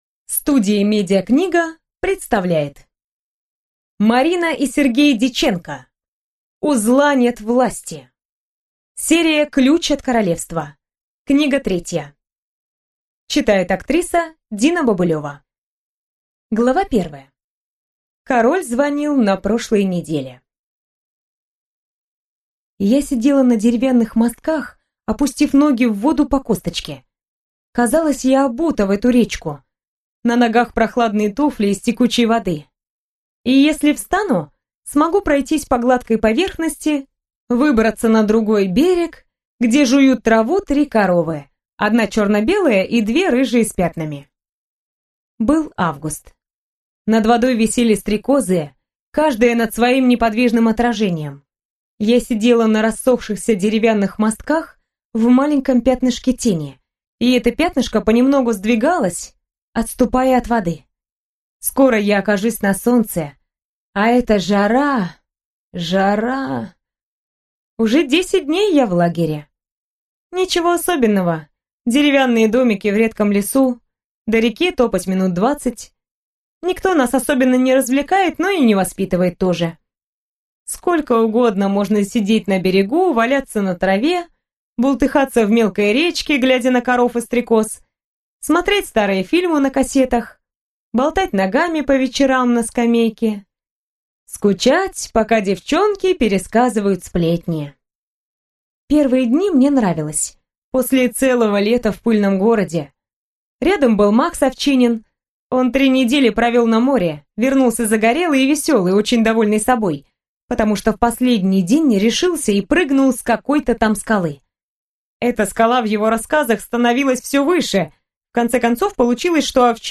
Аудиокнига У зла нет власти | Библиотека аудиокниг